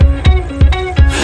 17 Floating In Credit Guitar.wav